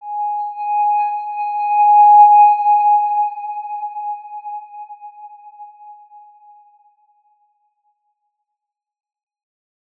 X_Windwistle-G#4-ff.wav